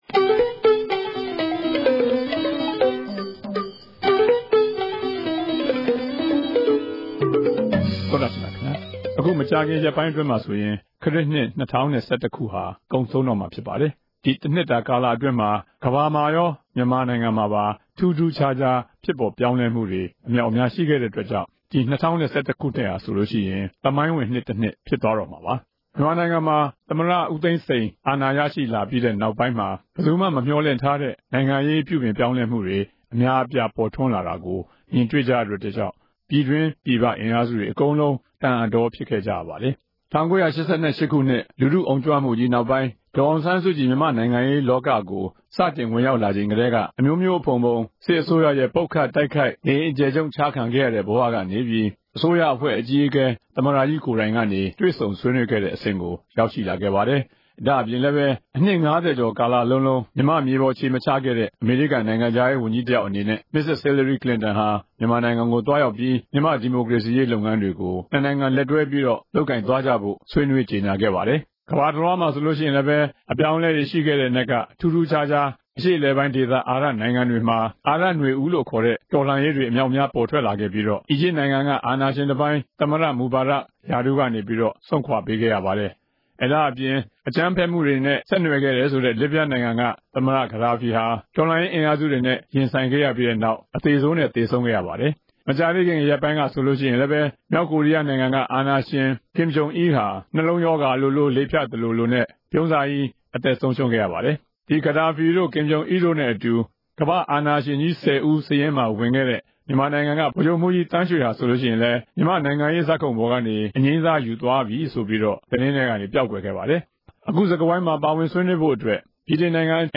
စီစဉ် တင်ဆက်တဲ့ တနင်္ဂနွေစကားဝိုင်း အစီအစဉ်မှာ- ၂ဝ၁၁ ခုနှစ်အတွင်း မြန်မာနဲ့ ကမ္ဘာတလွှားမှာ ပေါ်ထွက်ခဲ့တဲ့ ထူးထူးခြားခြား အပြောင်းအလဲတွေ အကြောင်းကို အခြေပြု ဆွေးနွေးထားကြပါတယ်။